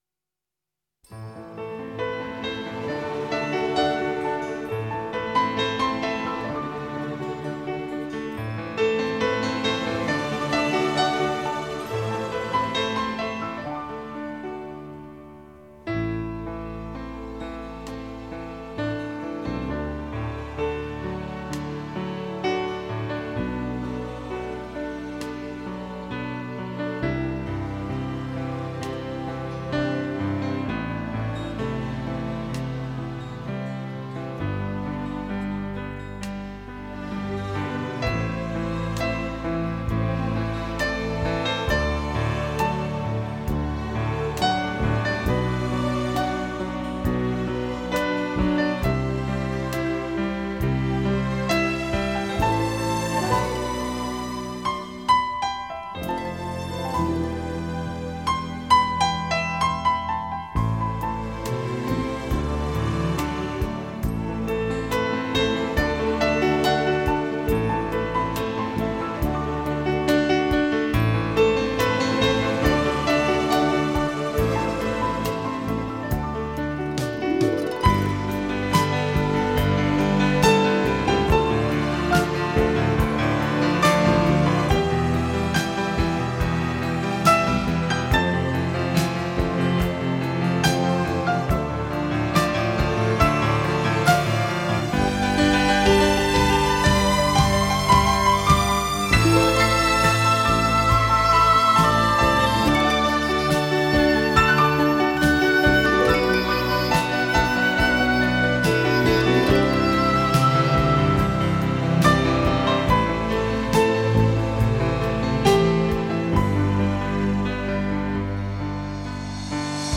2周前 纯音乐 6